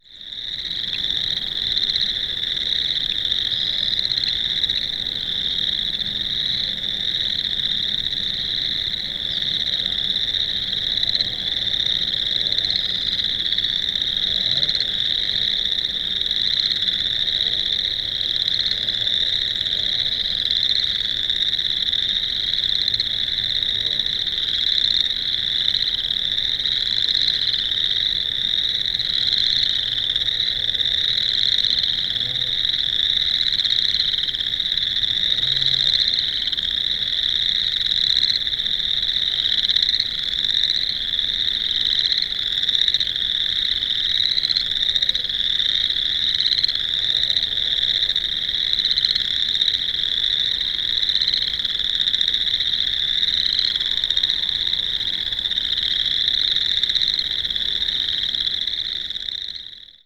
Sounds of Boreal Chorus Frog - Pseudacris maculata
The call of the Boreal Chorus Frog is a slow but short rasping noise rising in inflection and lasting 1/2 - 2 seconds. The sound is similar to stroking the small teeth of a pocket comb.
sound  This is a 60 second recording of the advertisement calls of a large group of Boreal Chorus Frogs calling from a flooded agricultural area at night in Burleigh County North Dakota in mid May, shown to the right. Cows can also be heard in the background.